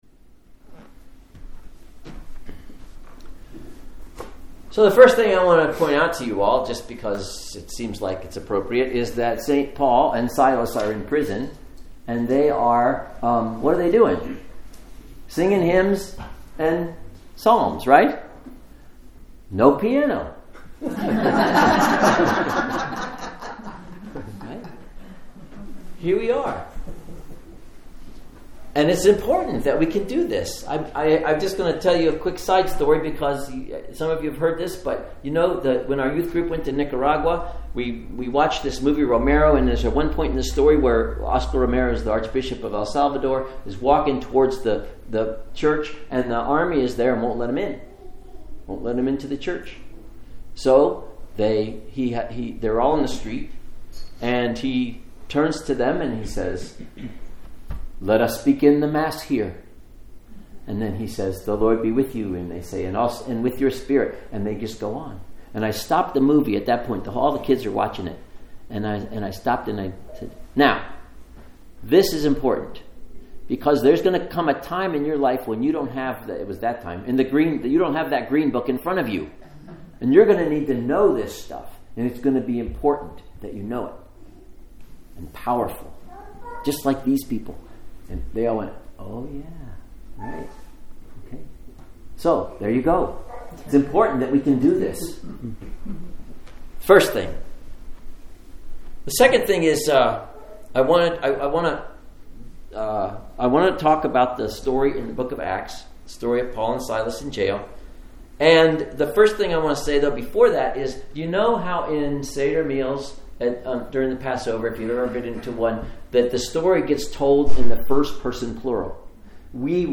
Sermons | Lake Chelan Lutheran Church
SEVENTH SUNDAY OF EASTER